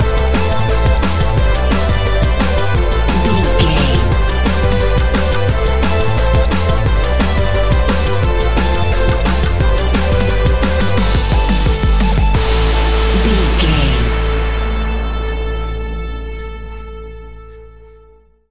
Epic / Action
Fast paced
Aeolian/Minor
aggressive
dark
driving
energetic
futuristic
synthesiser
drum machine
electronic
sub bass
synth leads
synth bass